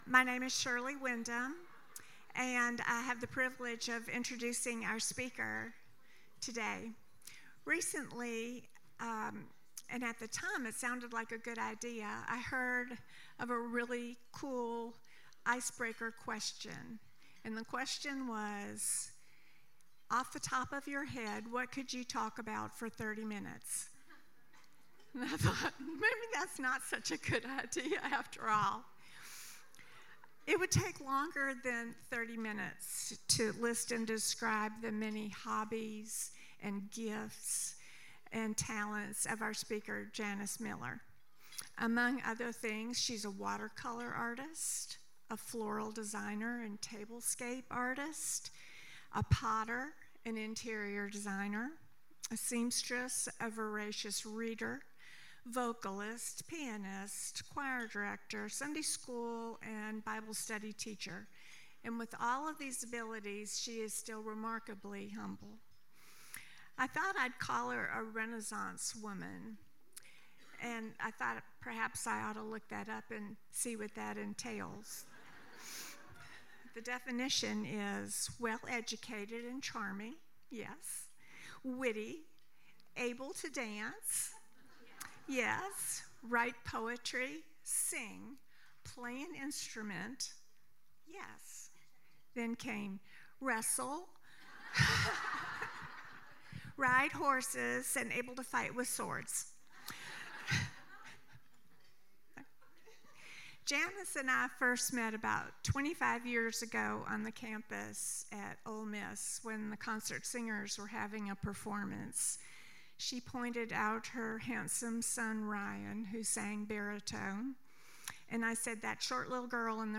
Womens-Christmas-Luncheon.mp3